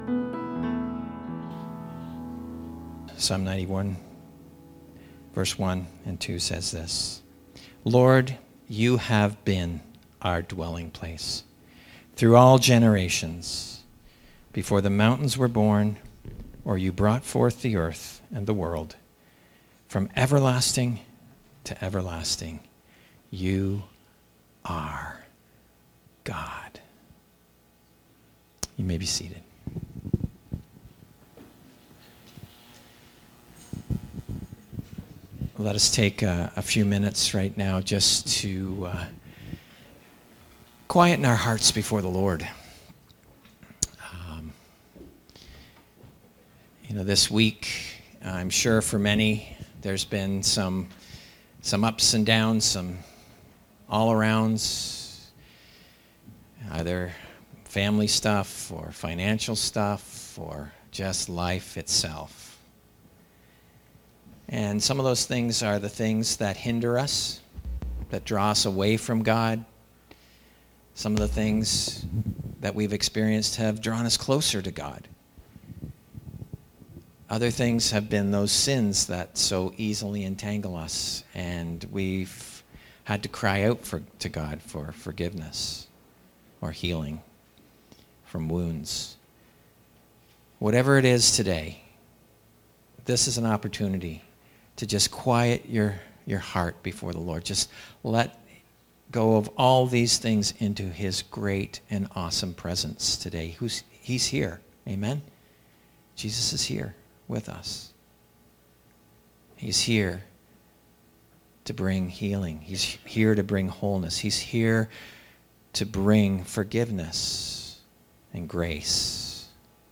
Sermons | Edmison Heights Baptist